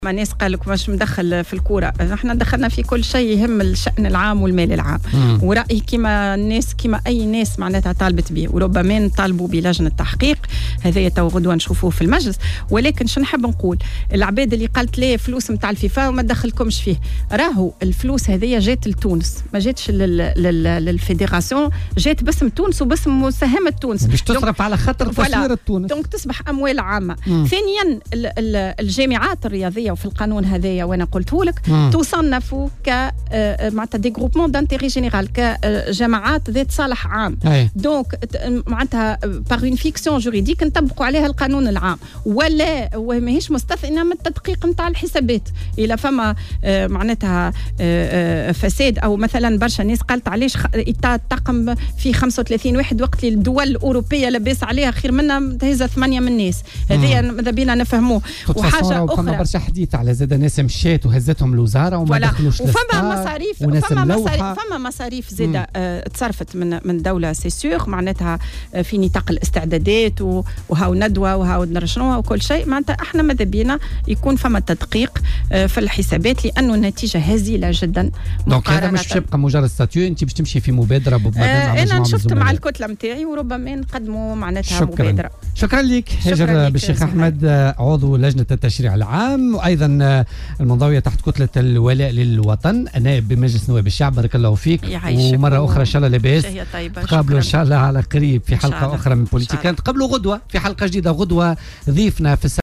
وقالت ضيفة بوليتيكا" على "الجوهرة اف أم" إن سيتم مناقشة الأمر في مجلس نواب الشعب وقد يتحول إلى مبادرة تشريعية.